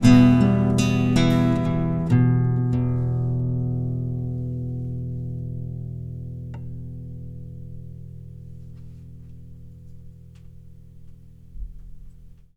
I also had to convert it to mp3 but the noise still shows up there too, I checked.
EDIT: Sorry, should add: They appear to be two separate issues; your initial audio clip and the one I am replying to which has the click/hiss at the end.
The original recording is from an XY mic setup for an acoustic guitar. It has a lot of bleed in from other instruments, including voice, and I expected Unmix Song to do well enough to save the recording.